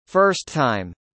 のように単語の最後のアルファベットと単語の最初のアルファベットが重なることで、本来2つ同じ単語を発生するべきところで、1つ分を省略し、前後の単語を繋げることができます。